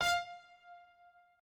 pianost22_023.ogg